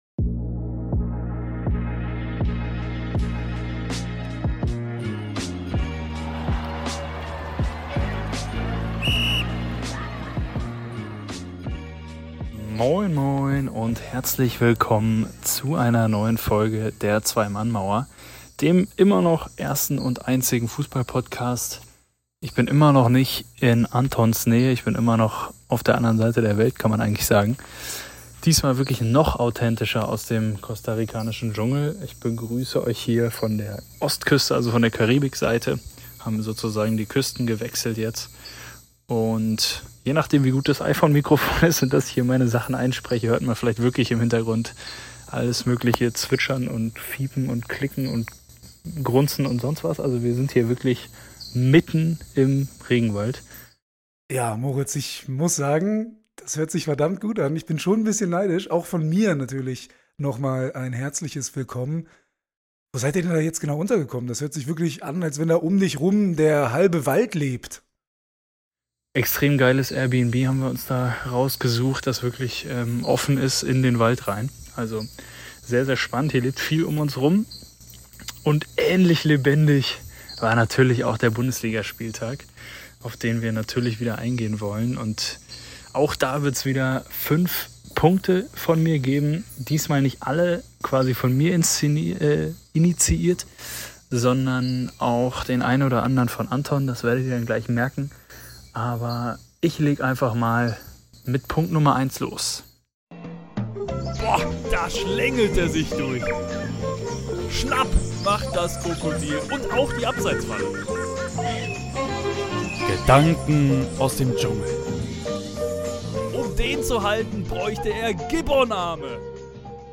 Grillenzirpen, Hupen und tierische Besuche!